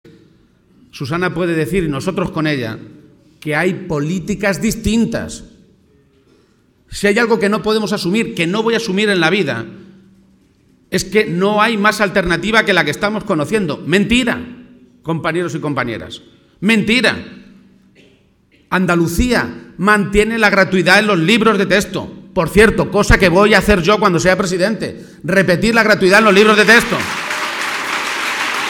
García-Page se pronunciaba de esta manera esta tarde, en Toledo, en un acto ante más de 800 personas en el que estaba acompañado por la Presidenta de Andalucía y secretaria general socialista en esa comunidad, Susana Díaz.
Audio García-Page acto PSOE Toledo 2